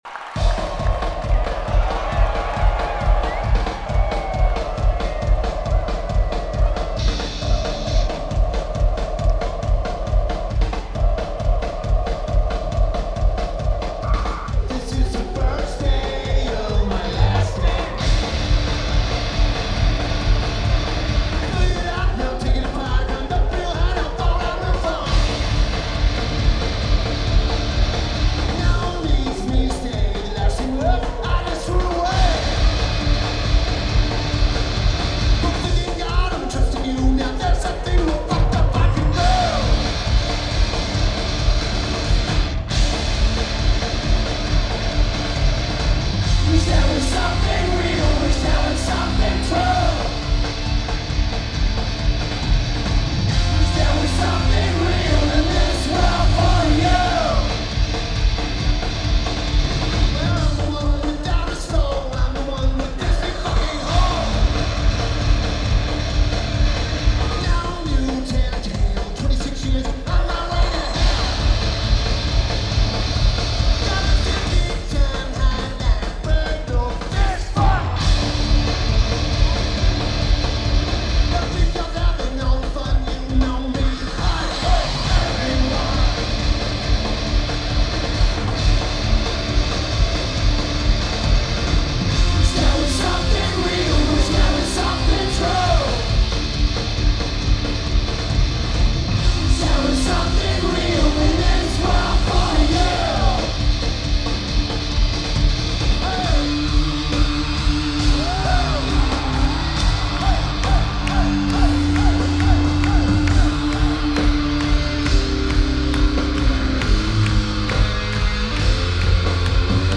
Lineage: Audio - AUD (AT853 (4.7k DT mod) + Sony PCM-A10)